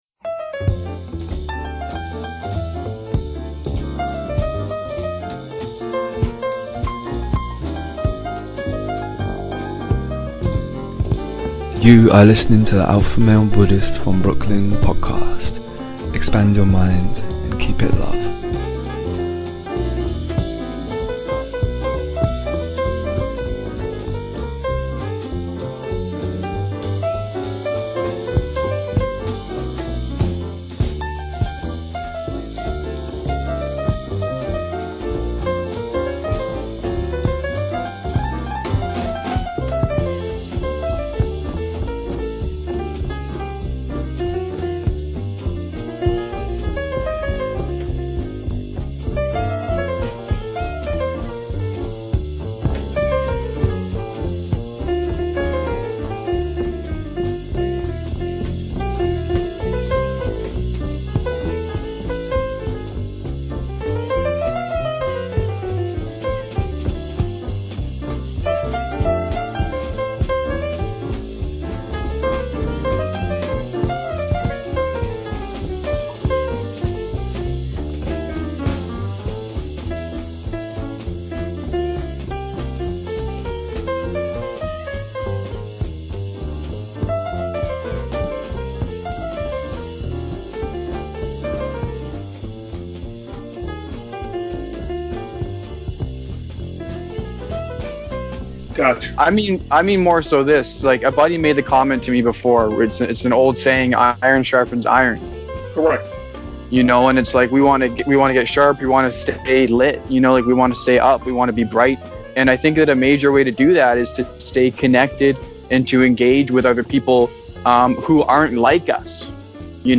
EP 132 - Interview